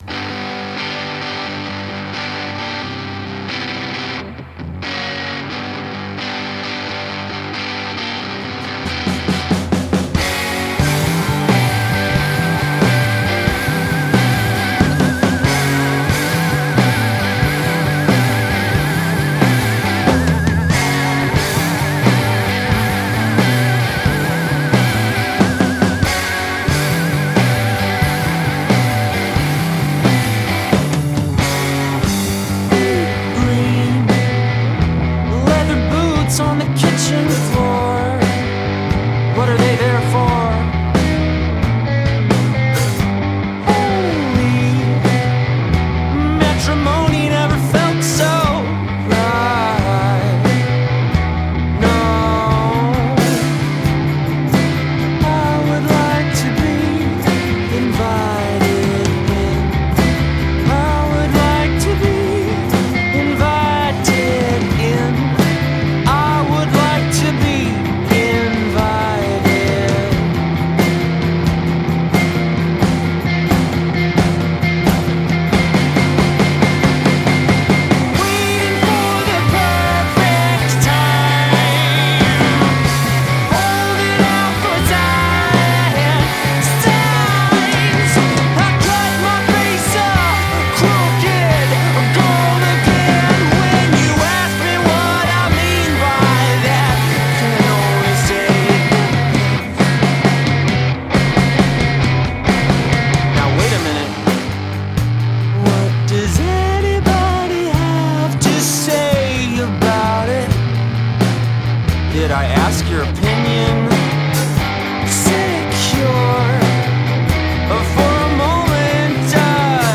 three-piece indie noise-pop band